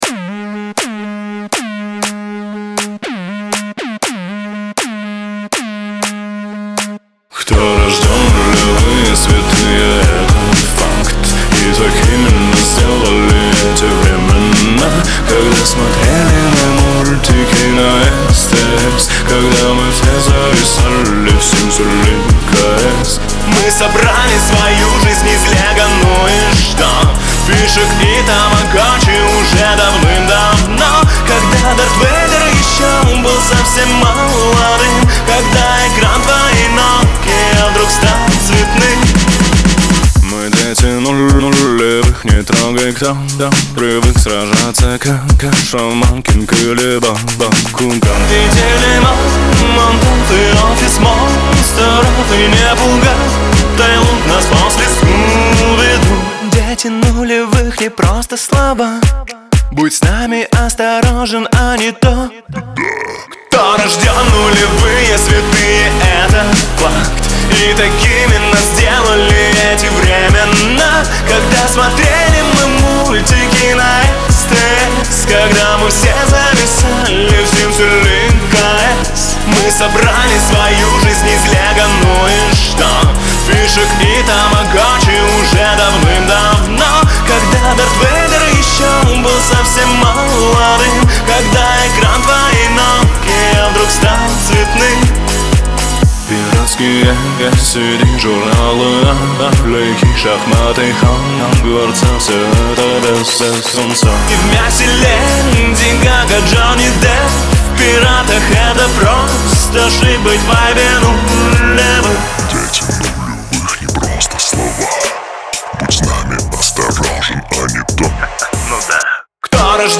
Жанр: ruspop